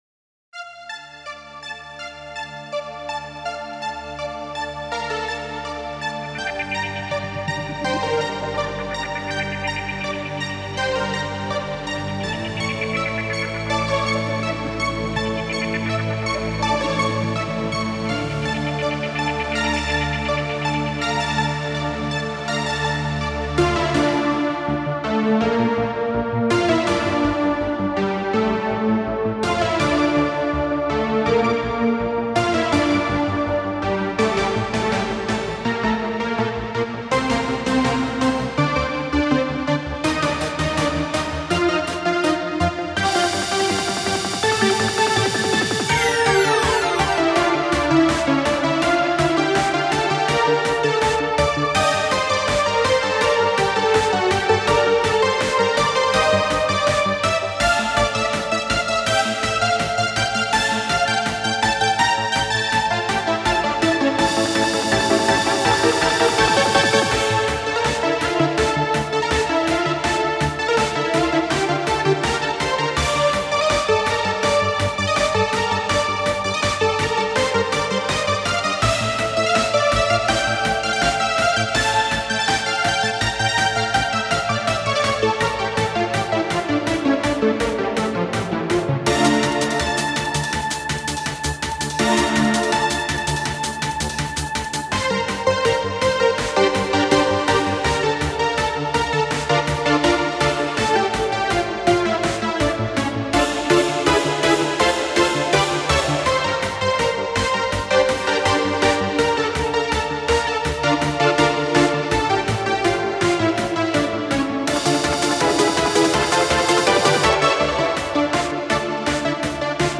Spacesynth Trance Techno Relax
Meditative Newage Space